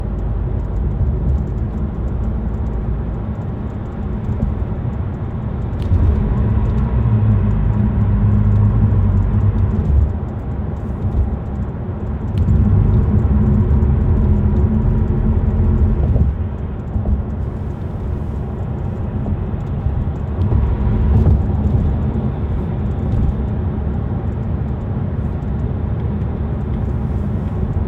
2022 Mazda3 Rear Window Ticking/Clicking
Doesn't matter if the road is flat or bumpy, it's still there. It's more constant when on an expressway.
There's road noise, but you should still be able to clearly hear it in the background.